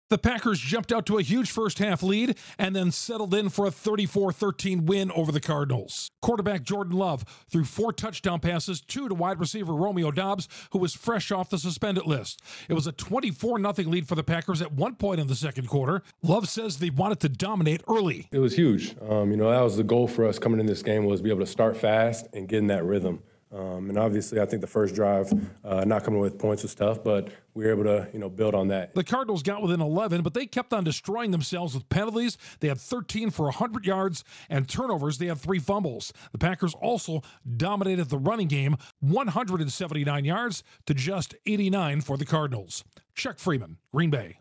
The Packers post an easy win over the Cardinals. Correspondent